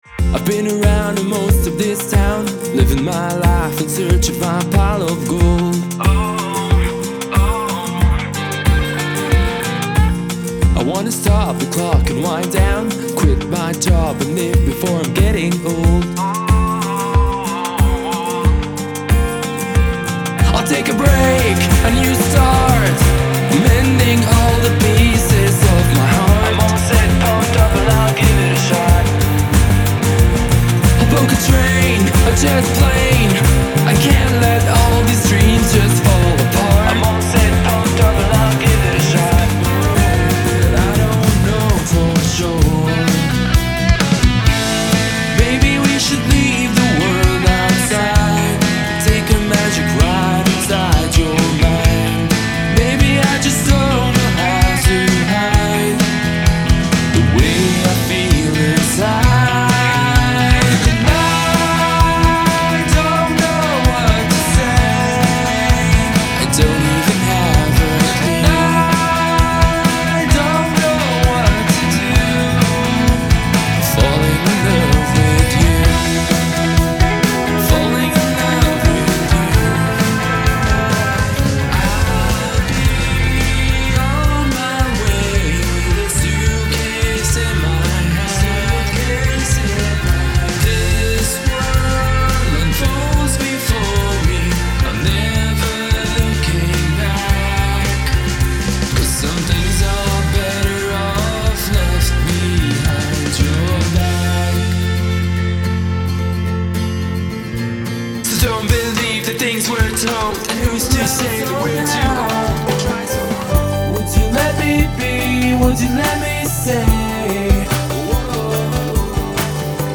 I am a singer/Songwriter and about to release an album ..
The songs are in the Alt/Acousit/Rock / Country category ..
Rock / folk international active music fans